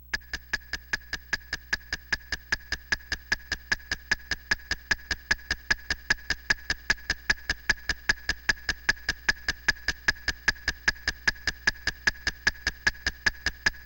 File:SSBM-SFX IT CLOCKBOMB COUNT.ogg
SSBM-SFX_IT_CLOCKBOMB_COUNT.ogg.mp3